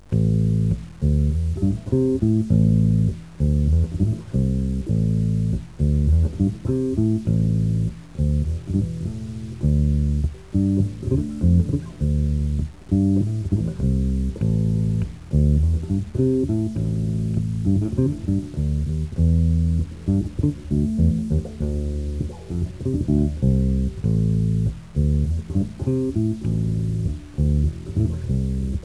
S svojo predelano bas kitaro Yamaha RBX 260, 8. maja 2011 With my modified bass guitar Yamaha RBX 260 on 8 May 2011
bas_linija1.wav